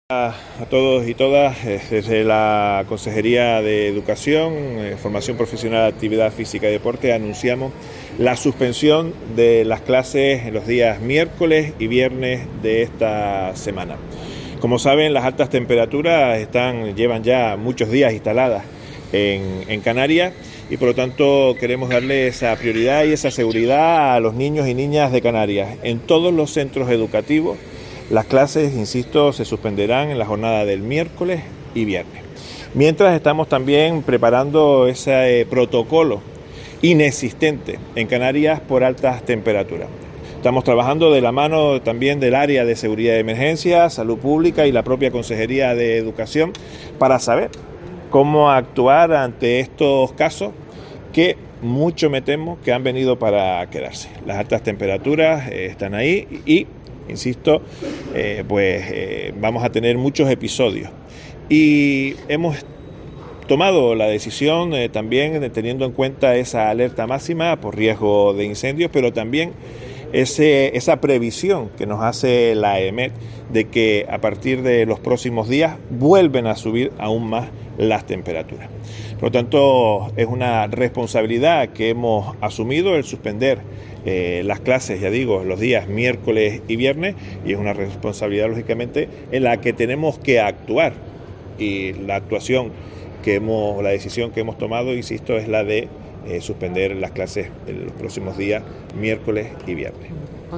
El consejero de Educación de Canarias, Pol Suárez, suspende las clases por calor